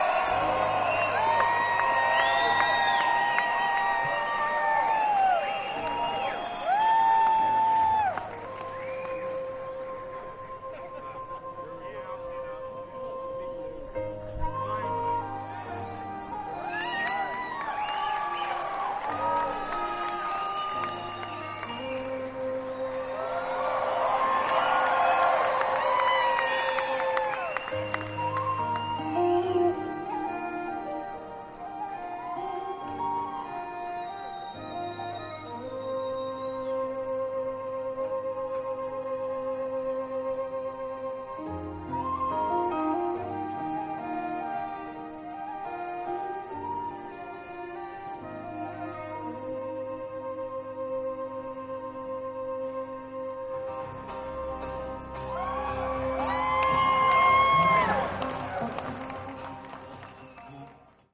format: 2 x 5" live
Place: Kansas City, MO, USA